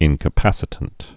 (ĭnkə-păsĭ-tənt)